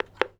phone_hangup_dial_03.wav